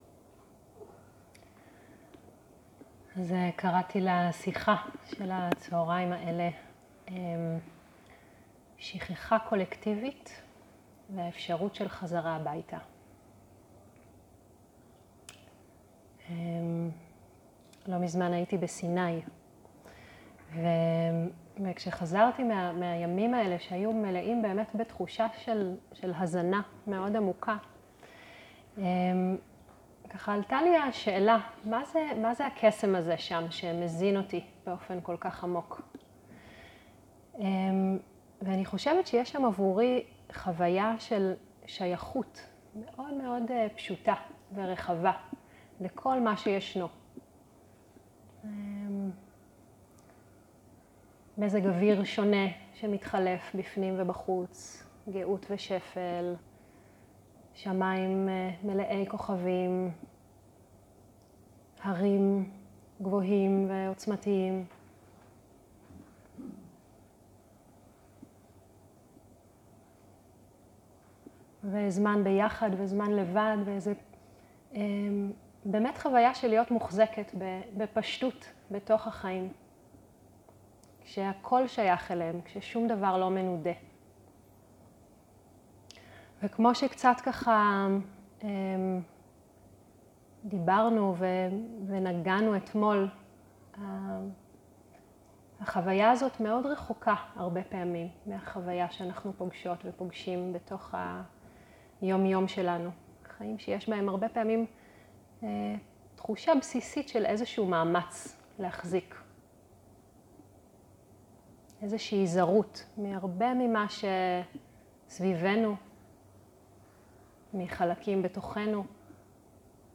ערב - שיחת דהרמה - שכחה קולקטיבית והאפשרות של חזרה הביתה
סוג ההקלטה: שיחות דהרמה